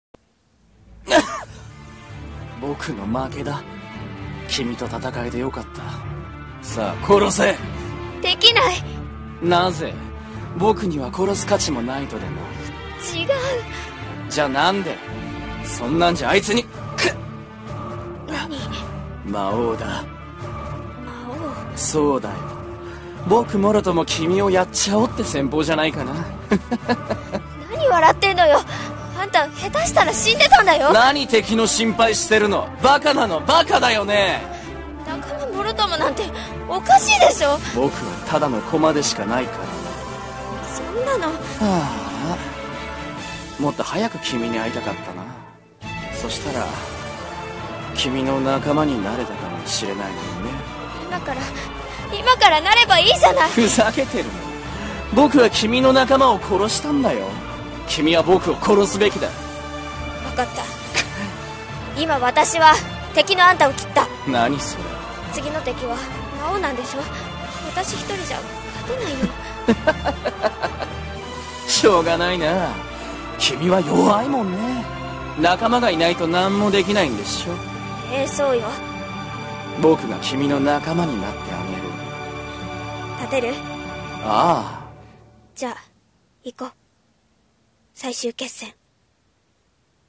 【声劇 掛け合い】